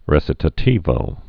(rĕsĭ-tə-tēvō, rĕchē-tä-)